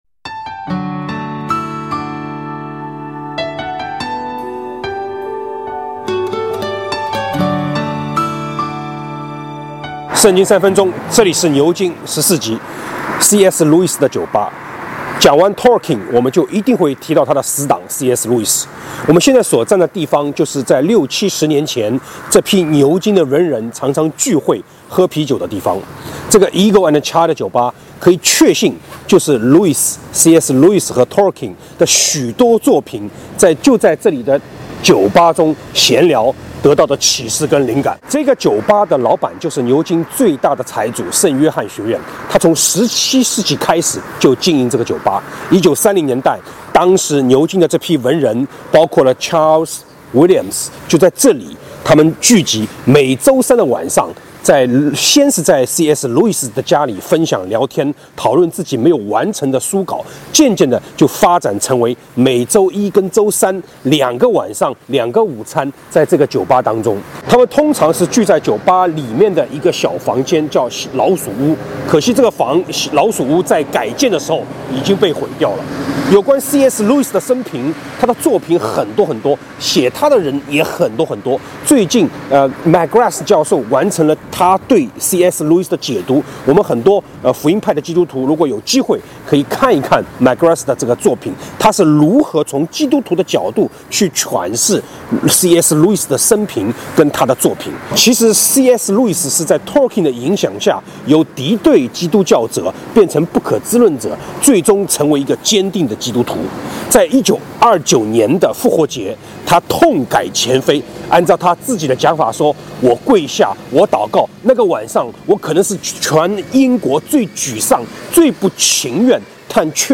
讲完托尔金就不可能不提一下他的死党CS路易斯。我们现在所在地就是60-70年前他们常常聚会喝啤酒的地方The Eagle and Child酒吧。可以确信，路易斯和托尔金的许多作品就是在这个酒吧中的闲聊中得到了启示和灵感。